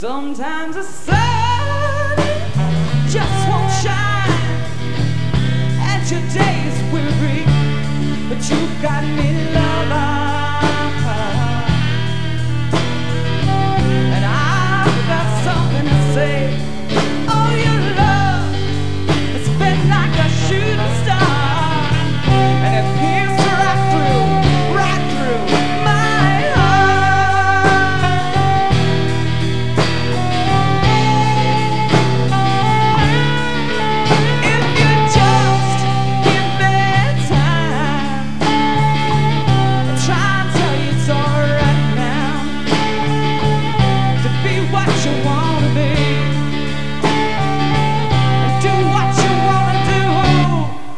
a soulful song